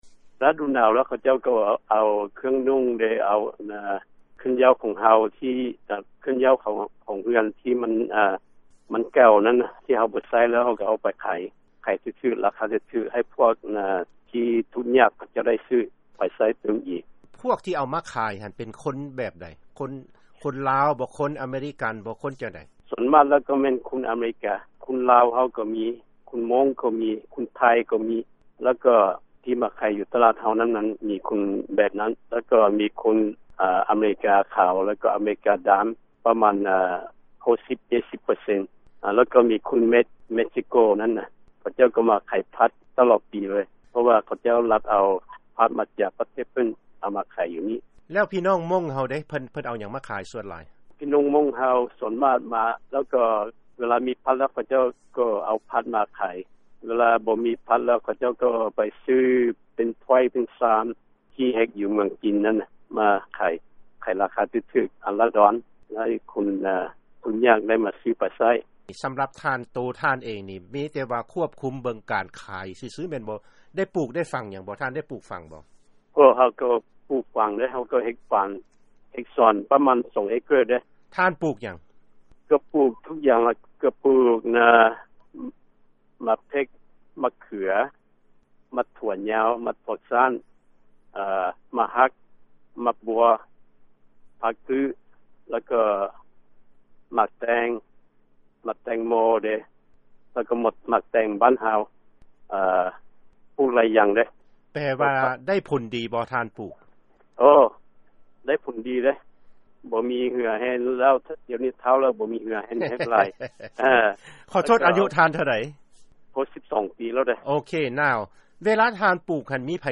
ເຊີນຟັງານສຳພາດ